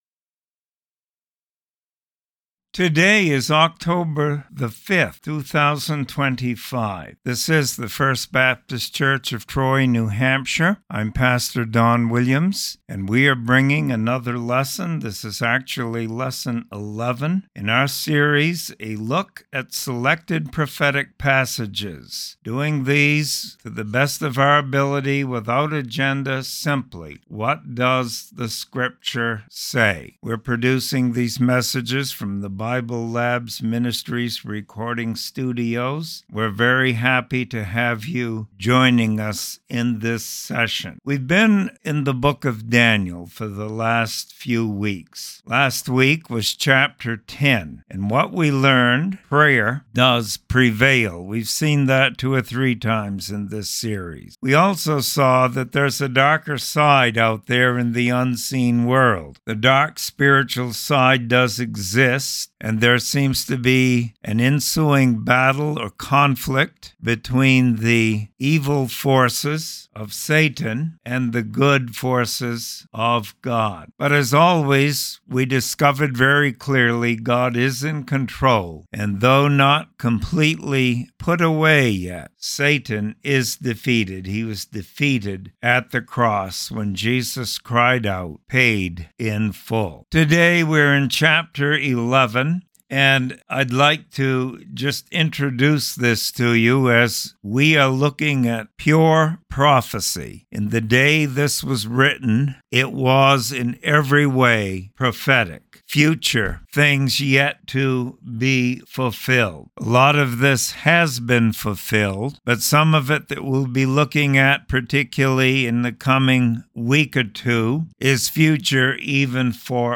Sermon Library | First Baptist Church of Troy NH